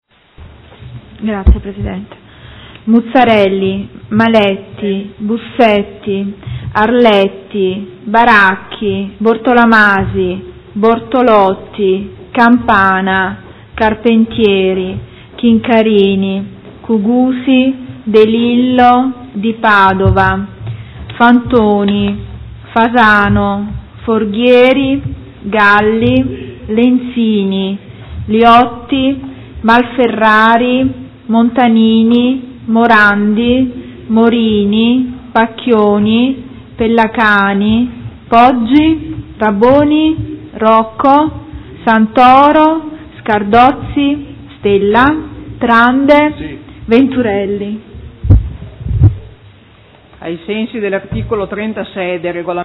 Seduta del 14/04/2015 Appello.
Segretaria